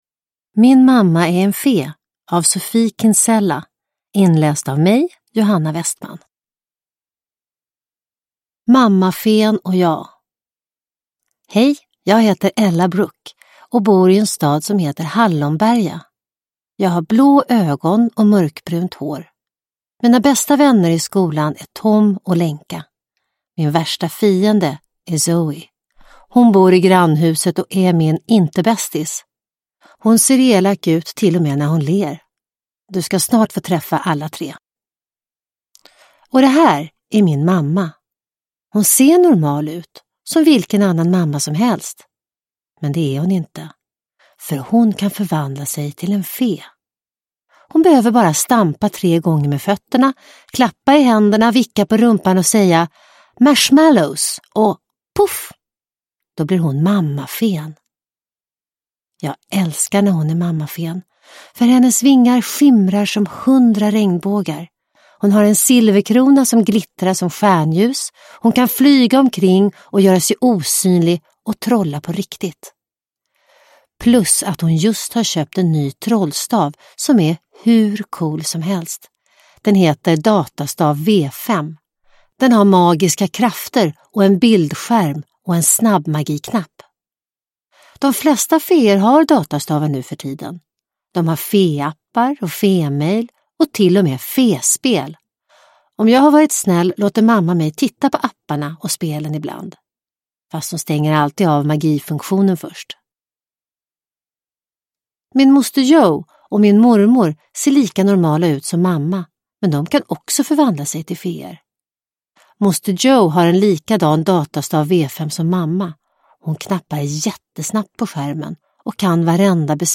Min mamma är en fe – Ljudbok